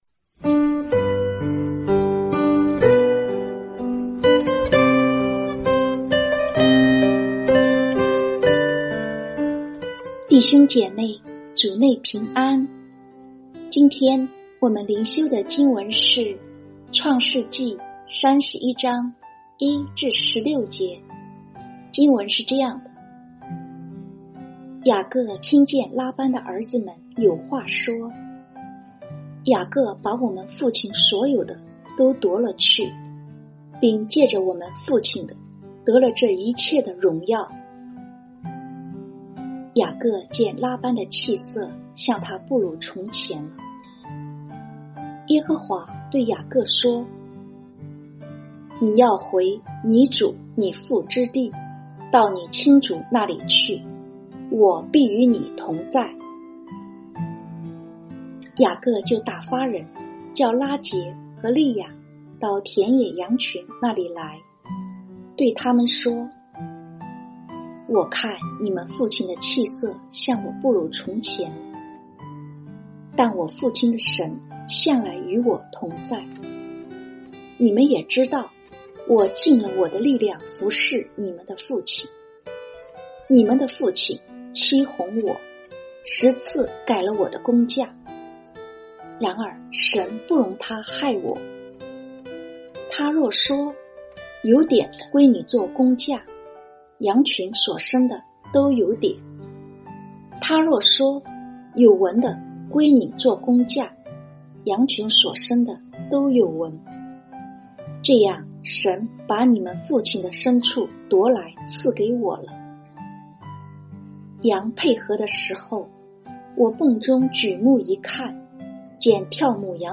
這是把通讀整卷創世記和每天靈修結合起來的一個計劃。每天閱讀一段經文，聆聽牧者的靈修分享，您自己也思考和默想，神藉著今天的經文對我說什麼，並且用禱告來回應當天的經文和信息。